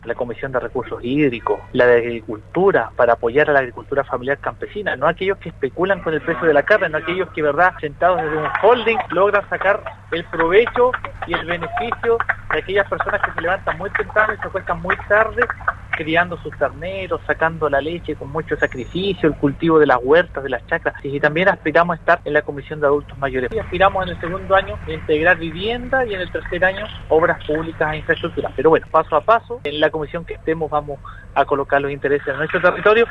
Este último, en medio de la votación para la confirmación de la primera mesa directiva del período, que durará siete meses, contestó el llamado de Radio Sago para comentar sus sensaciones y proyecciones para el nuevo trabajo parlamentario. Barría, exalcalde de Purranque, adelantó que, tras conversaciones con la bancada de su partido, espera integrar tres comisiones legislativas específicas.